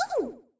TT_hurtsoft.ogg